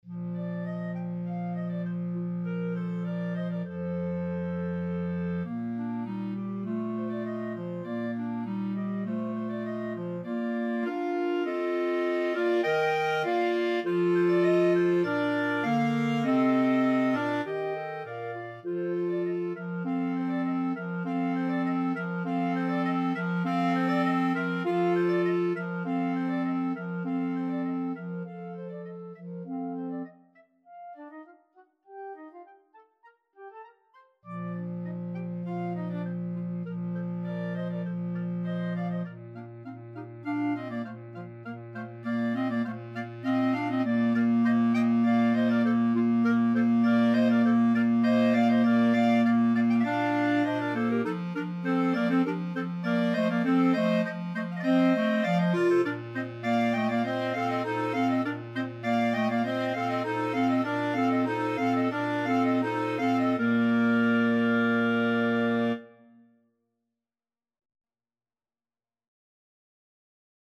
Clarinet 1Clarinet 2Clarinet 3Bass Clarinet
2/4 (View more 2/4 Music)
Classical (View more Classical Clarinet Quartet Music)